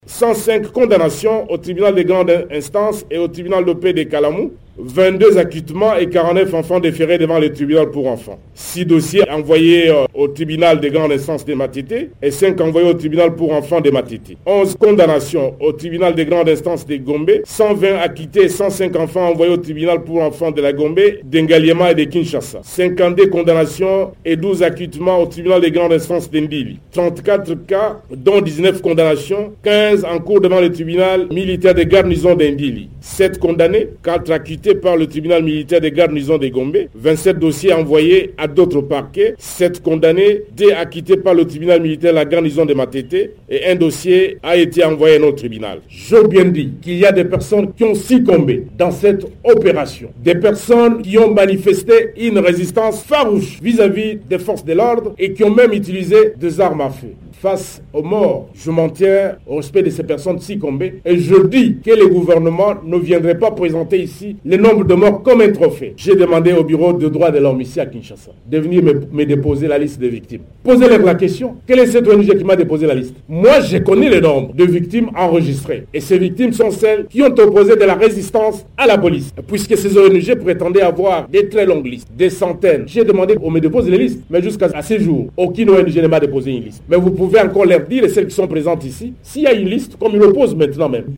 Le ministre de l’Intérieur de la RDC défend une opération antibanditisme critiquée par les ONG, au micro de Top Congo FM